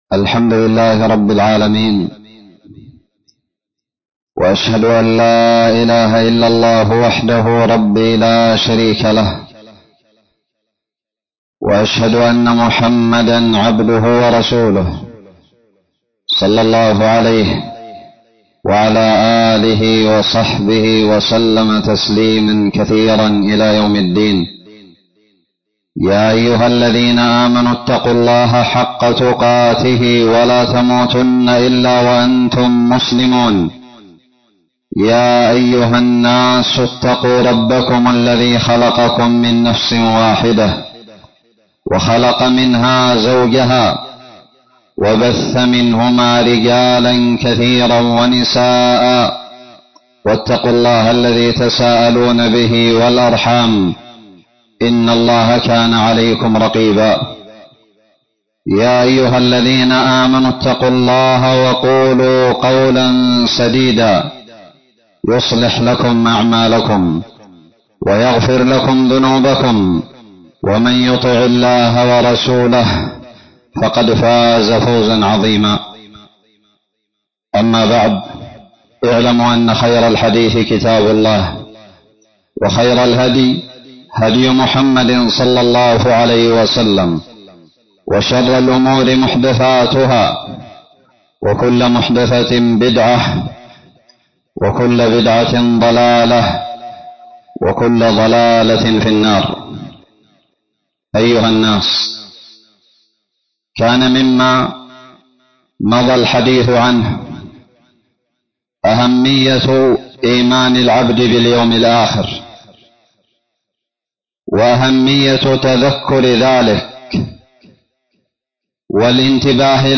خطب الجمعة
ألقيت بدار الحديث السلفية للعلوم الشرعية بالضالع في 15جمادى الأولى 1441هــ